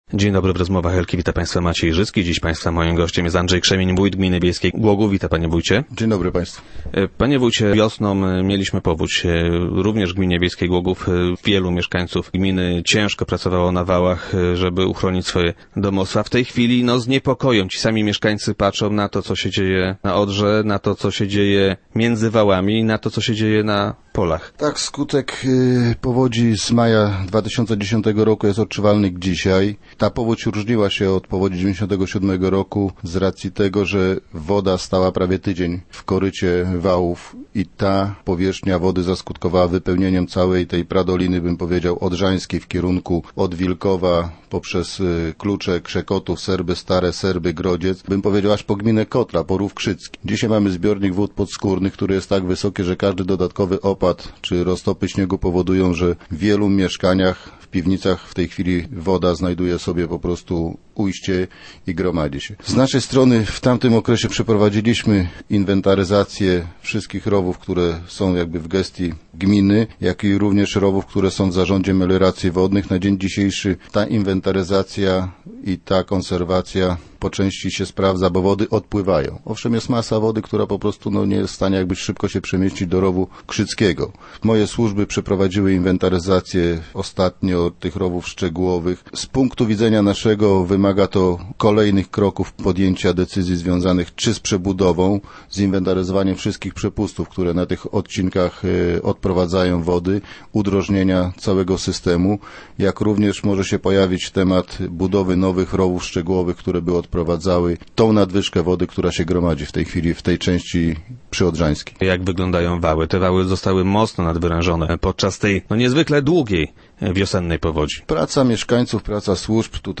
- Bardziej niepokojąca jest ilość wód podskórnych - twierdzi Andrzej Krzemień, wójt gminy wiejskiej Głogów, który był gościem Rozmów Elki.